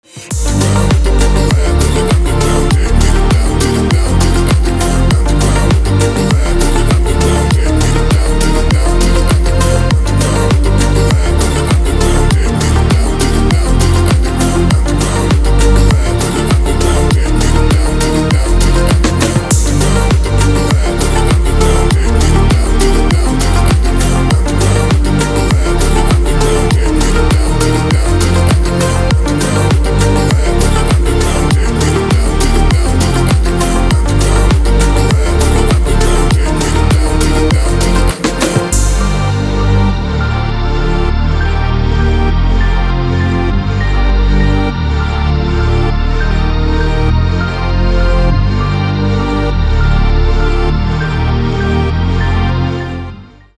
Progressive House / Organic House